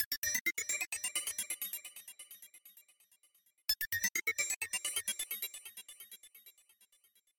Tag: 130 bpm Ambient Loops Fx Loops 1.24 MB wav Key : Unknown